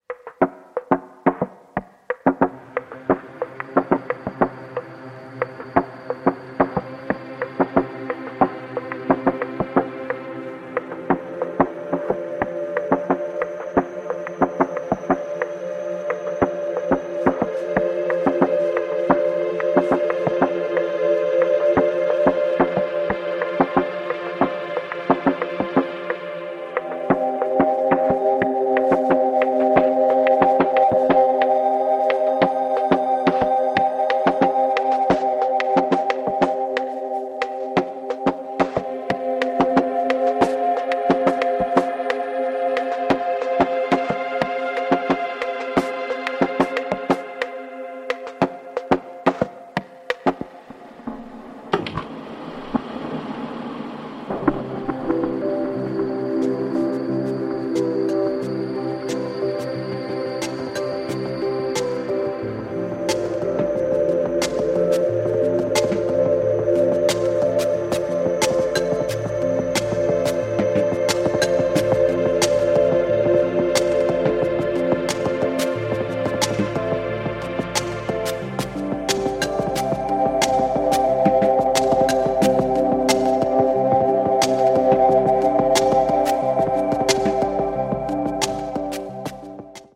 Beautiful, soothing and moody house tracks with breaks vibes
House Breaks